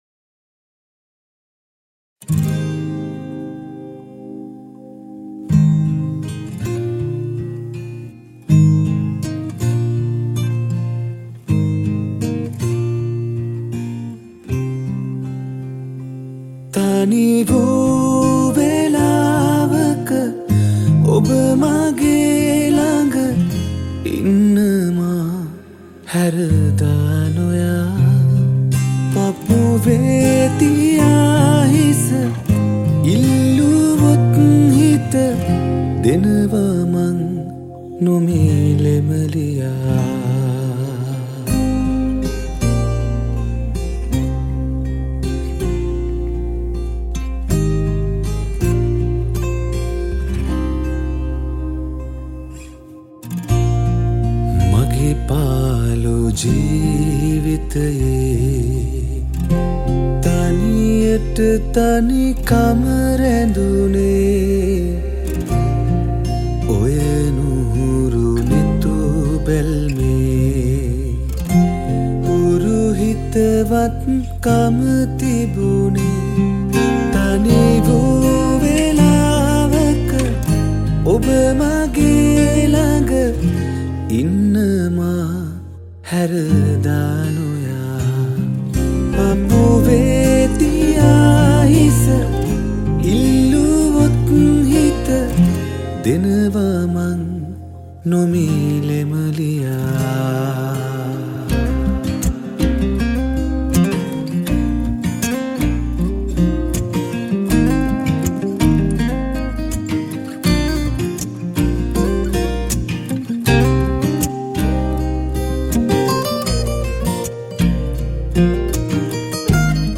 New Music arrangement & Guitarist